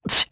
Ezekiel-Vox_Damage_jp_02.wav